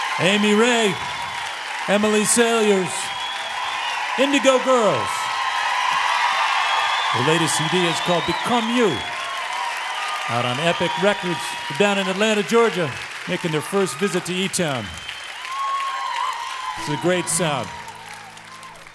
lifeblood: bootlegs: 2002-02-12: e town at boulder theatre - boulder, colorado (pre fm broadcast)